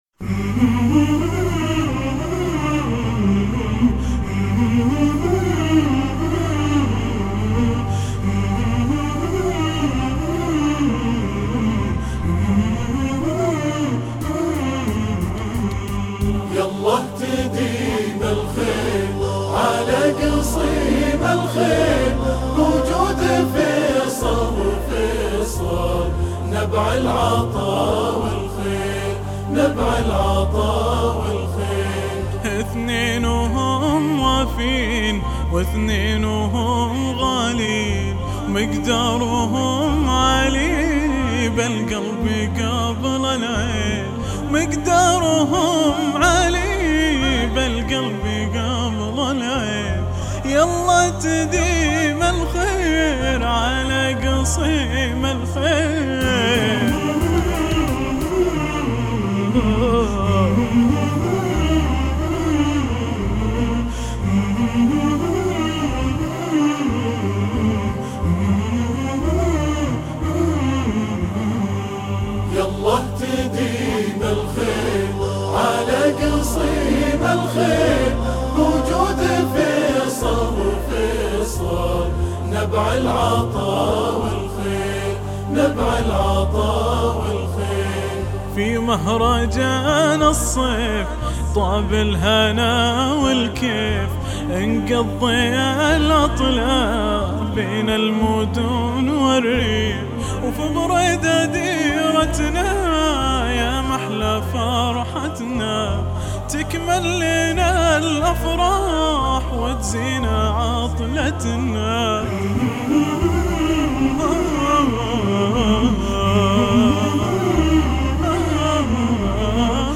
احبتي اليكم النشيدتين اللتين قدمتا في حفل
كورال